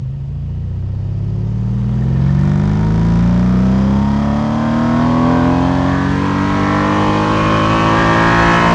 rr3-assets/files/.depot/audio/Vehicles/v8_10/v8_10_Accel.wav
v8_10_Accel.wav